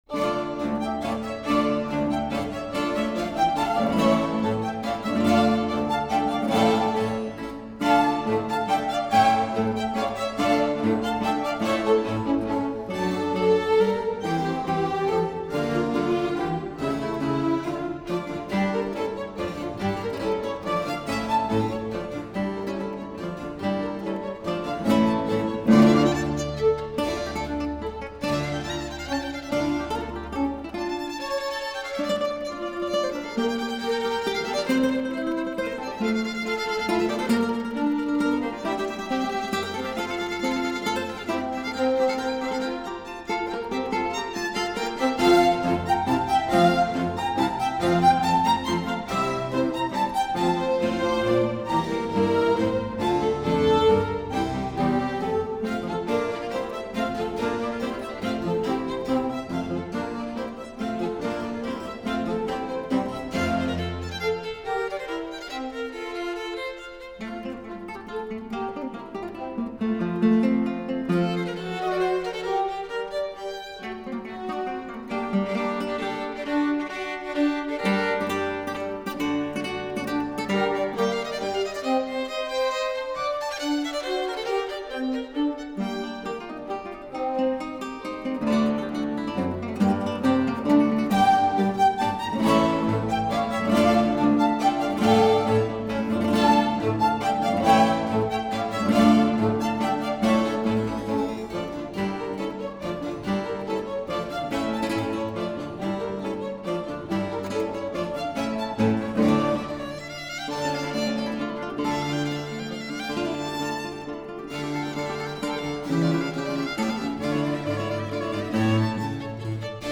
Concerto In D Minor For Viola D'Amore & Lute